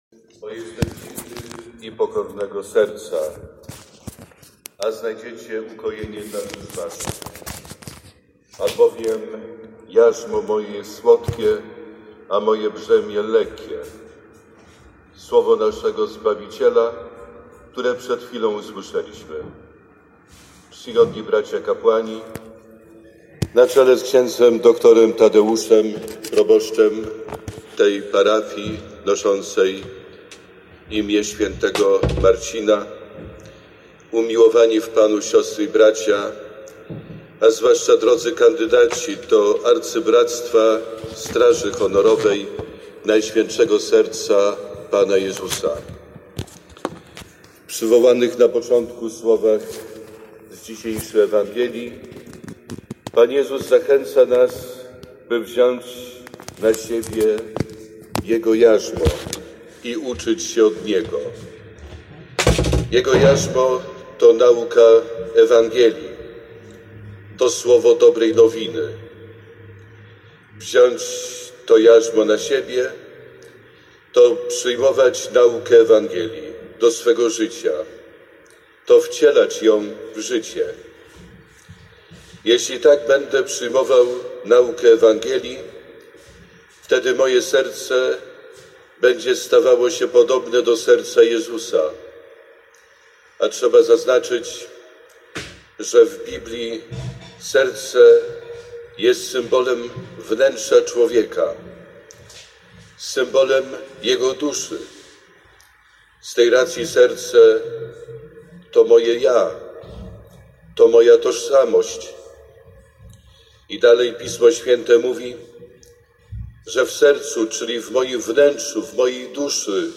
Homilia ks. bpa Wiesława Szlachetki wygłoszona podczas uroczystego przyjęcia do Straży Honorowej NSPJ 3 sierpnia 2020 roku w parafii św. Marcina w Sierakowicach.